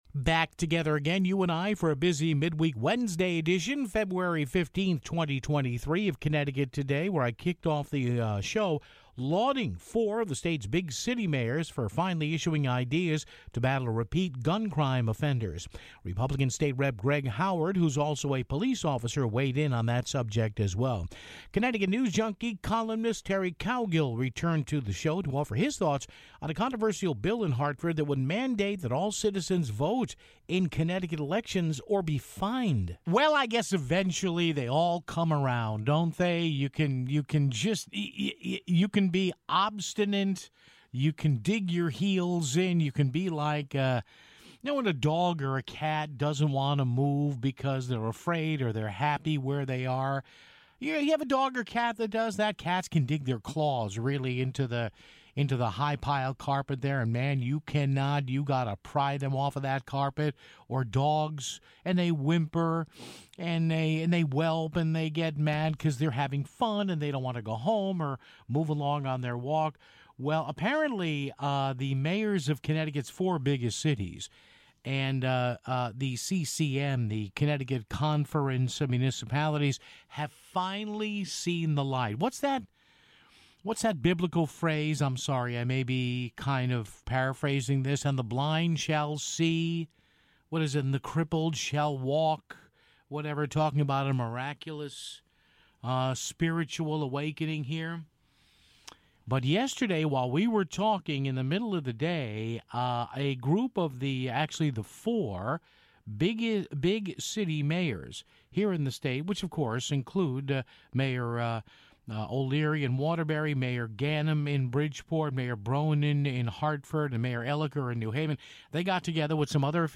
GOP State Rep. Greg Howard - who's also a police officer - weighed in on that subject (13:23).